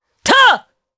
Morrah-vox-get_jp.wav